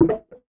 Pinball Game Shoot 1.wav